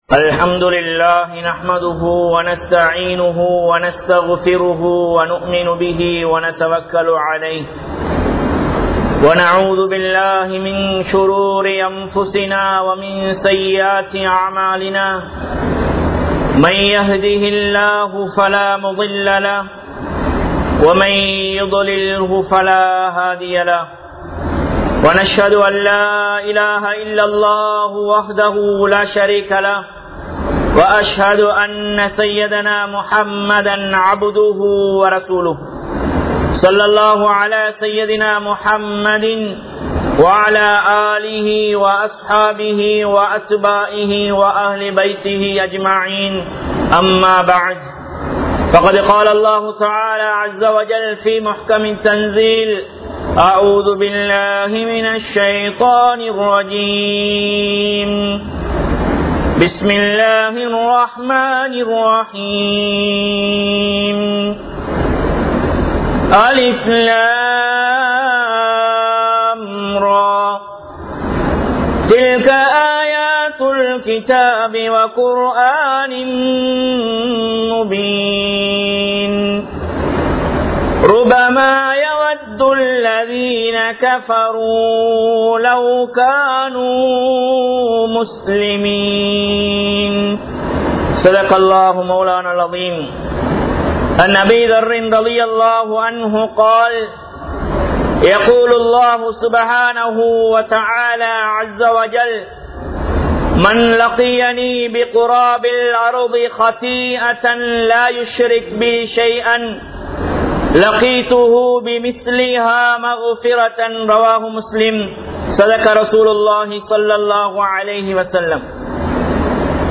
Tamil Kalappu Thirumanagalin Vilaivuhal (தமிழ் கலப்பு திருமணங்களின் விளைவுகள்) | Audio Bayans | All Ceylon Muslim Youth Community | Addalaichenai